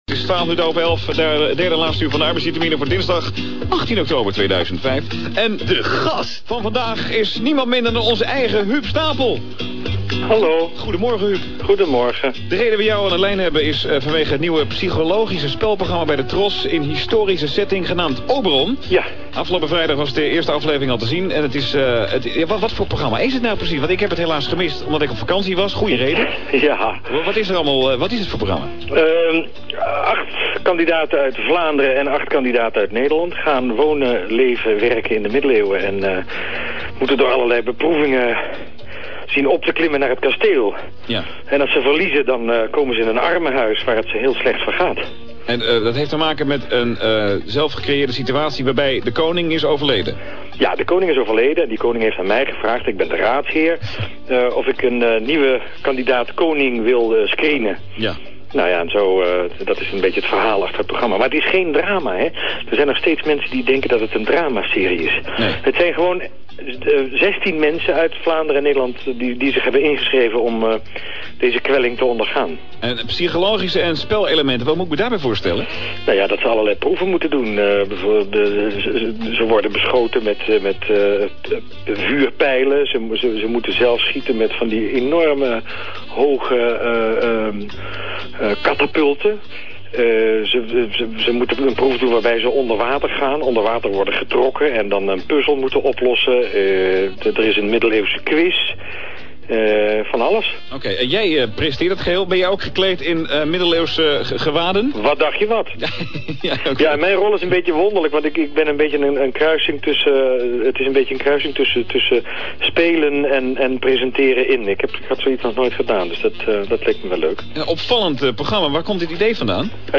Elke werkdag is bij 3FM tussen 9.00 en 12.00 uur het programma Arbeidsvitaminen te horen met DJ Gerard Ekdom. Op Dinsdag 18 oktober belde Gerard Huub Stapel op om te praten over Oberon!